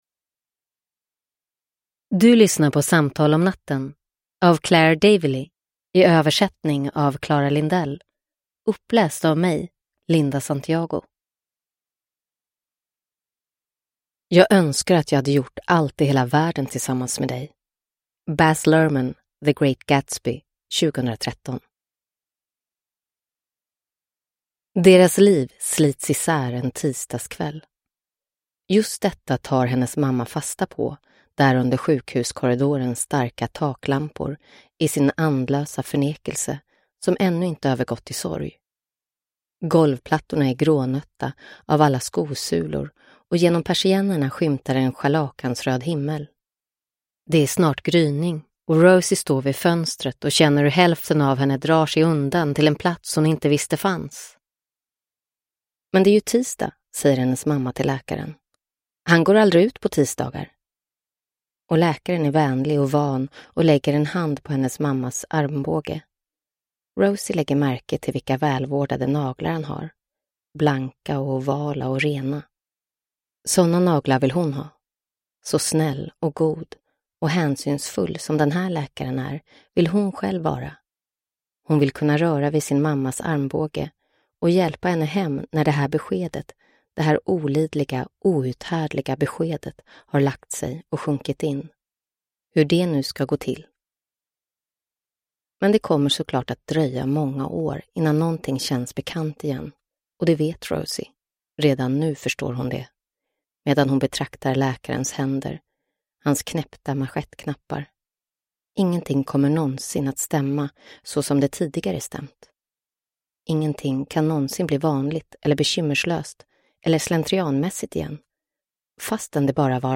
Nedladdningsbar Ljudbok
Ljudbok